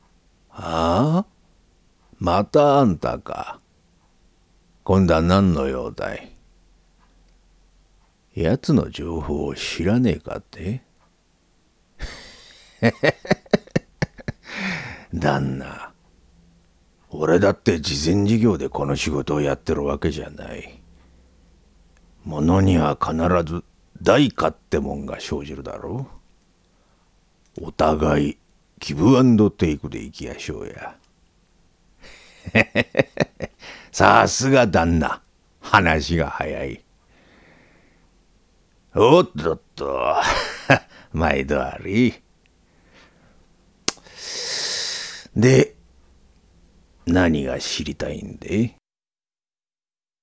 ボイスサンプル
老人